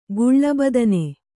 ♪ guḷḷa badane